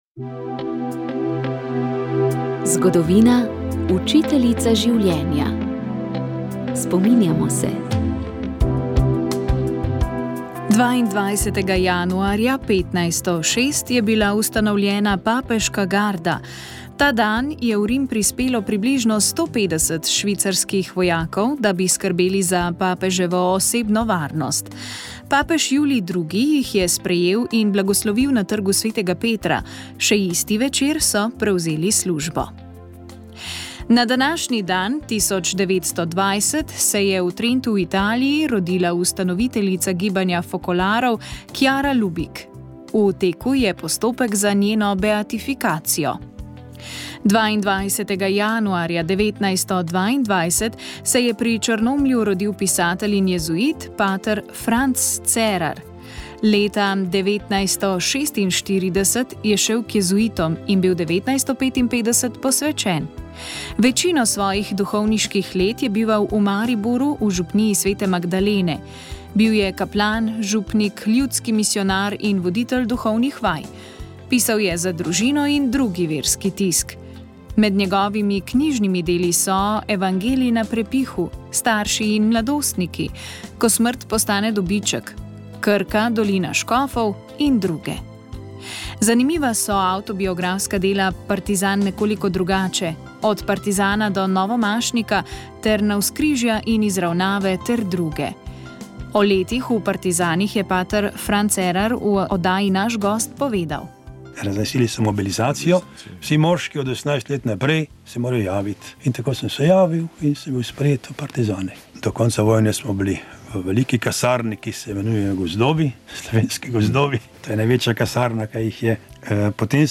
Tudi ženske so bile pomemben del kot sledilke, zbiralke informacij, agentke. V današnji oddaji smo slišali pričevanje ene od njih.